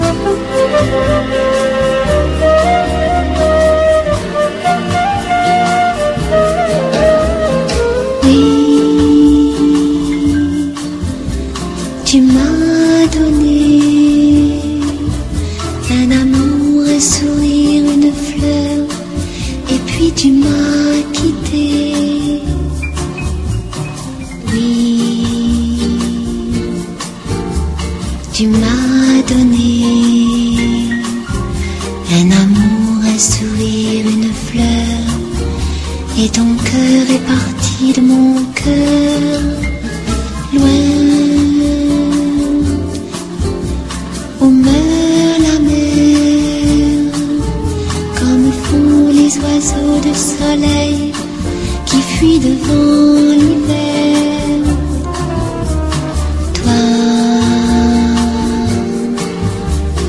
ROCK / SOFTROCK. / SUNSHINE POP / 60'S ROCK
オルガンと熱いハーモニーをたっぷり効かせた、疾走するサビの高揚感たっぷりのファンキー・ナンバー